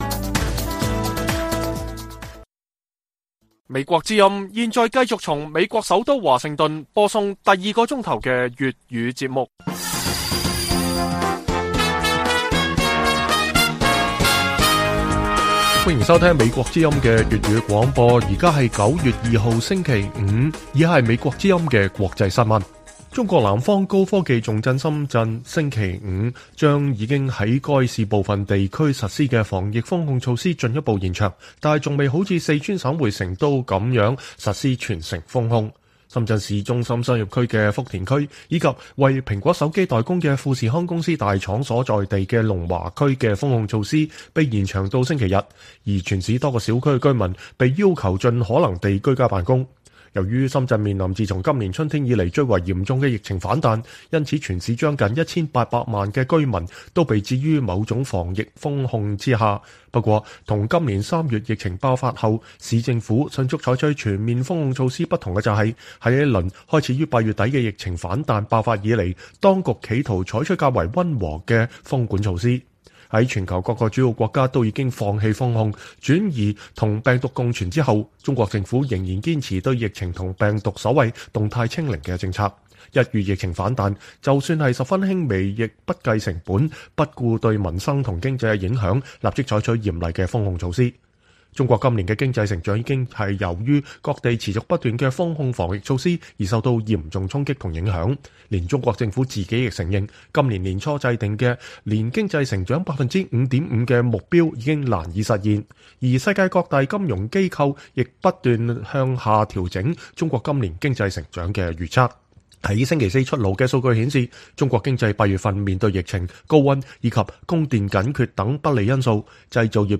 粵語新聞 晚上10-11點: 中國成都封城才開始，深圳新冠封控又延長